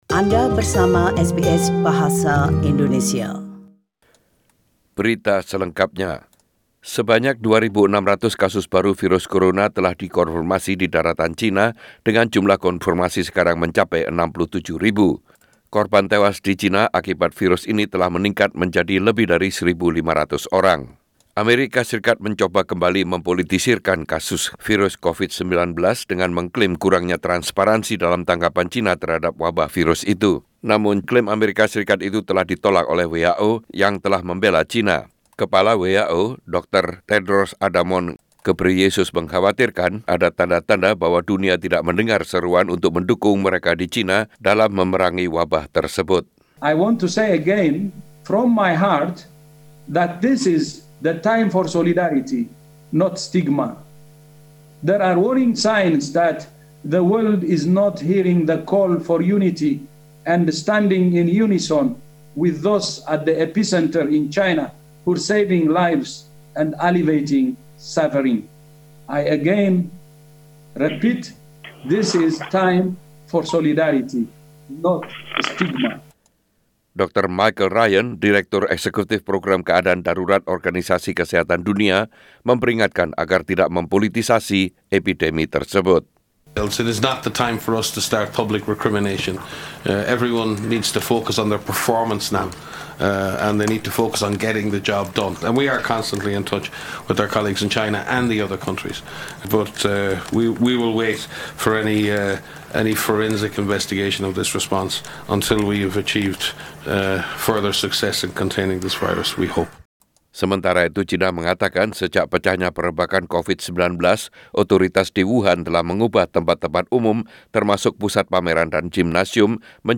Warta Berita Radio SBS dalam Bahasa Indonesia - 16 Feb 2020